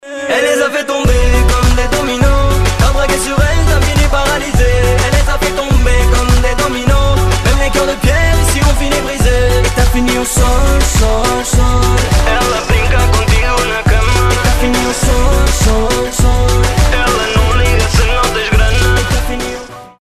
• Качество: 256, Stereo
поп
dance
vocal
Заводная композиция на французском!